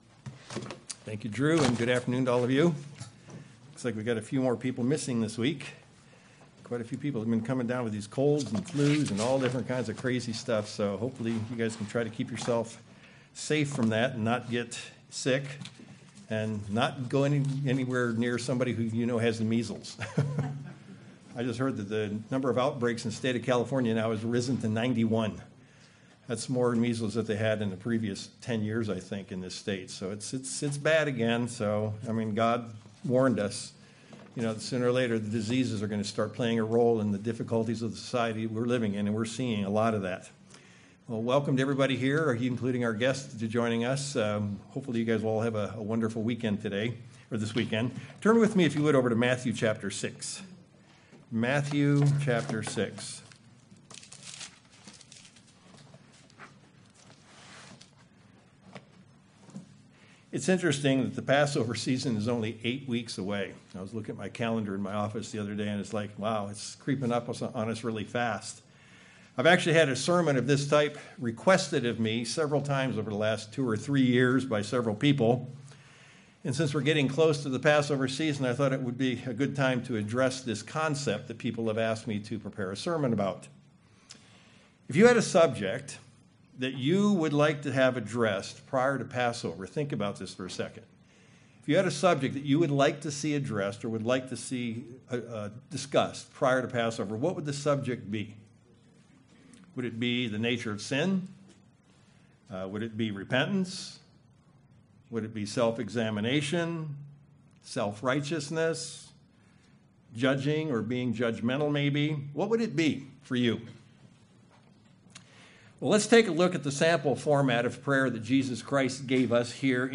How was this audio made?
Given in Sacramento, CA